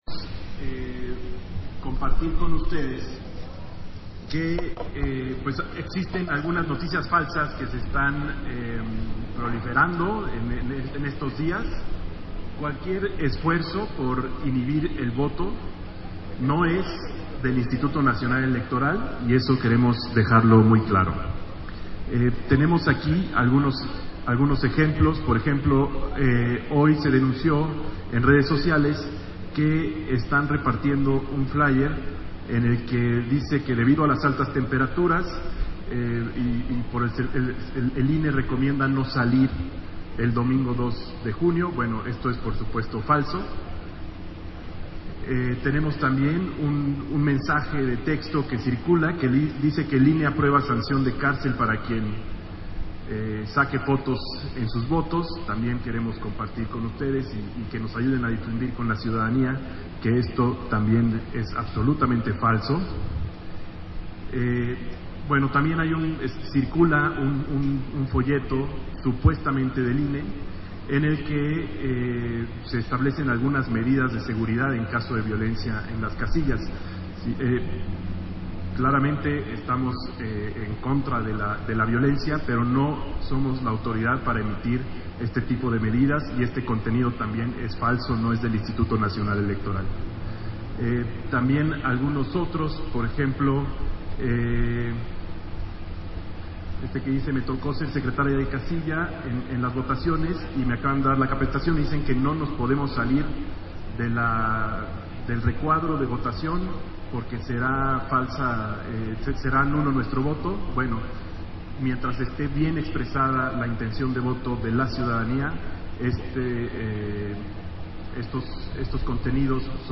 310524_AUDIO_-CONFERENCIA-CAPACITACION-ELECTORAL - Central Electoral